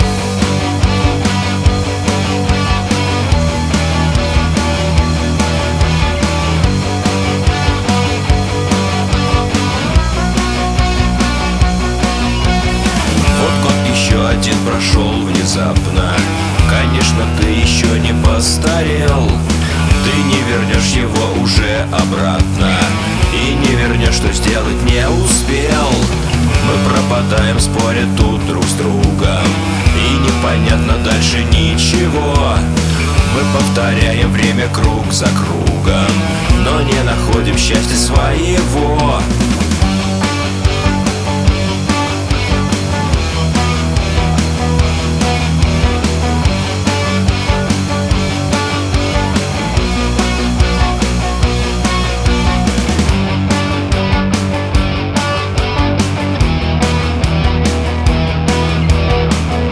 я принимал участие в записи и сведении. и должен быть не аккуратный как и все остальные пэды.